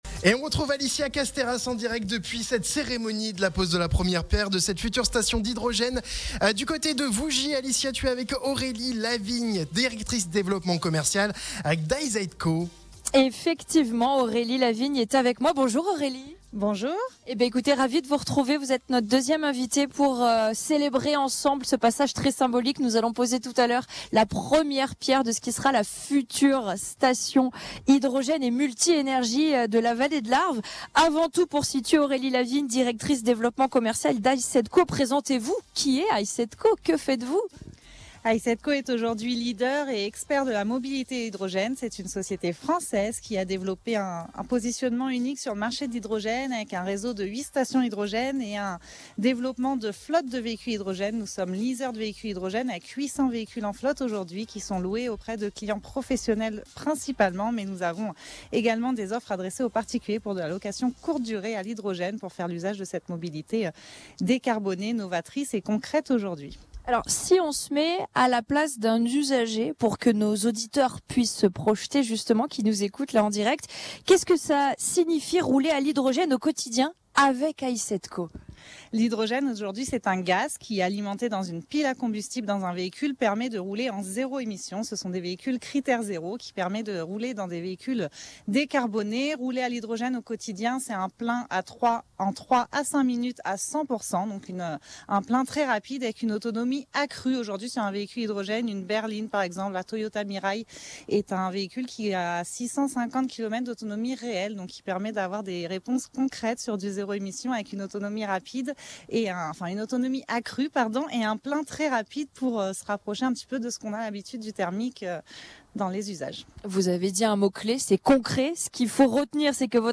Ce jeudi 27 juin, Radio Mont Blanc était en direct de Vougy pour une émission spéciale à l’occasion de la pose de la première pierre de la future station multi-énergies Arv’Hy.